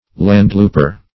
Search Result for " landlouper" : The Collaborative International Dictionary of English v.0.48: Landlouper \Land"loup`er\, n. [D. landlooper, lit., landrunner; land land + loopen to run.